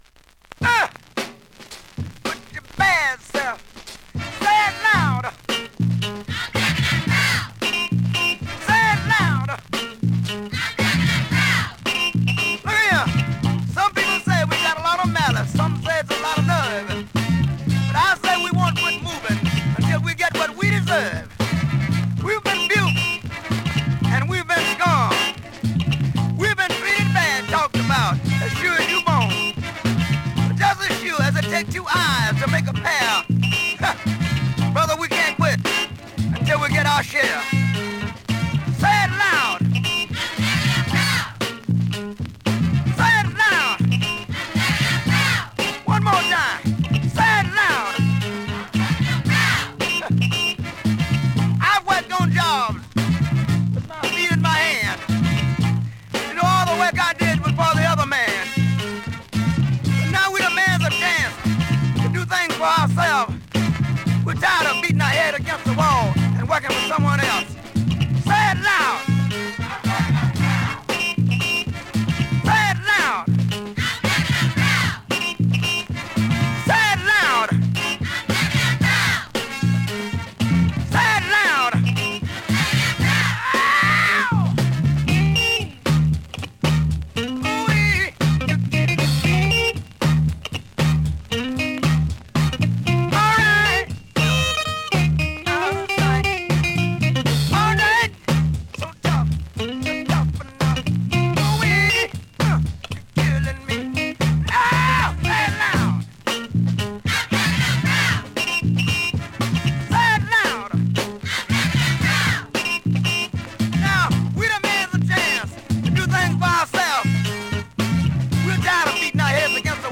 現物の試聴（両面すべて録音時間５分２８秒）できます。